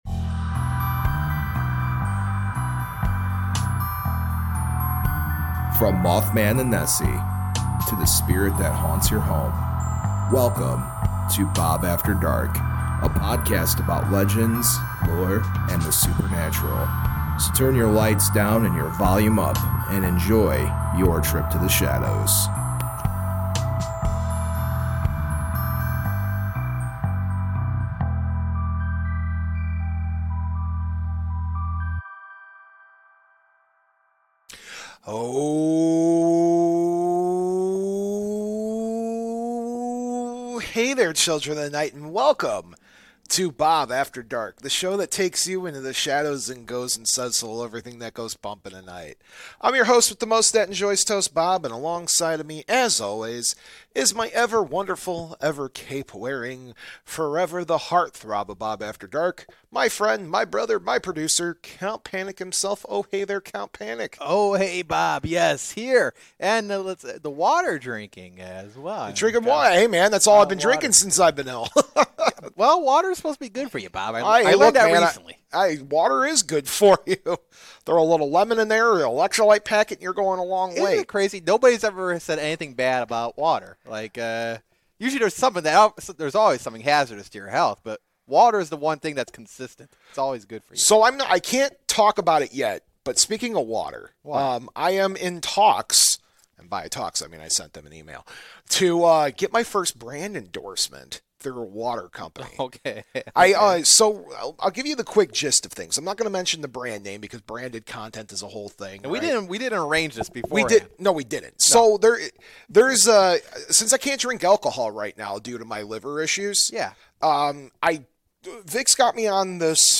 166 - Sorbet Jungle Interview and Huggin' Molly.